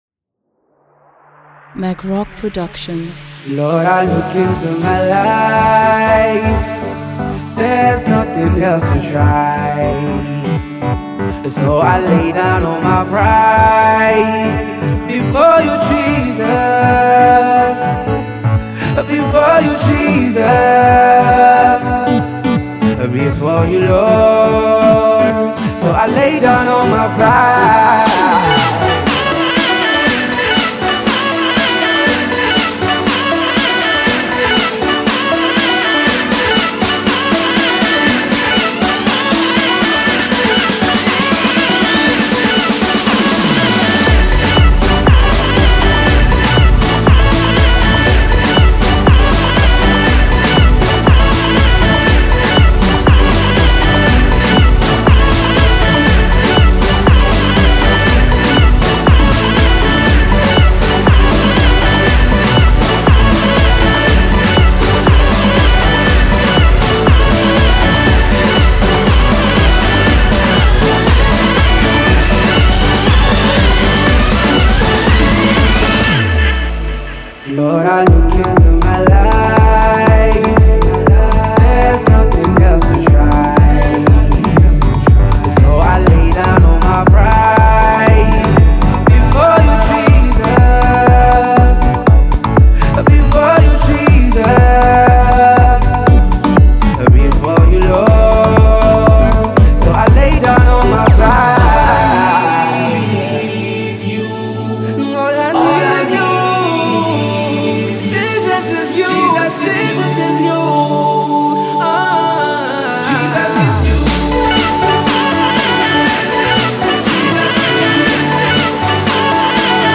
This is sure going to get you on your feet.